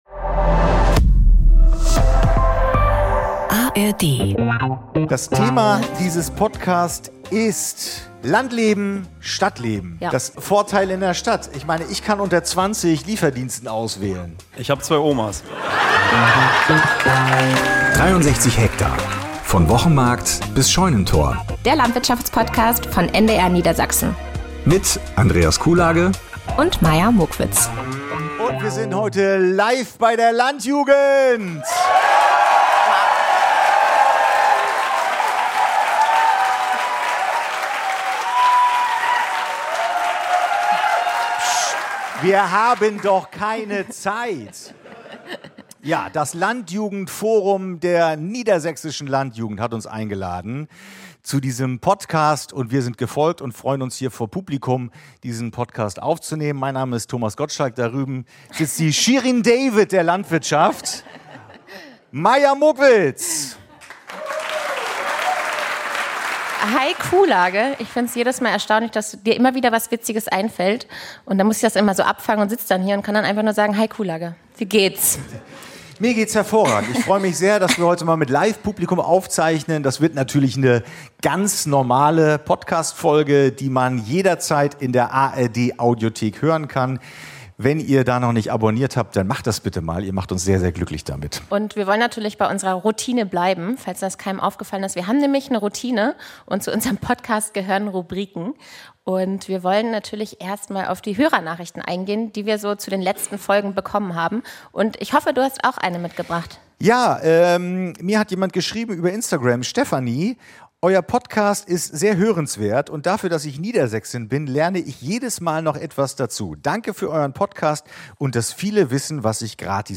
Stadtleben vs. Landleben: Live-Podcast bei der Landjugend (#14) ~ 63 Hektar - der Landwirtschafts-Podcast von NDR Niedersachsen Podcast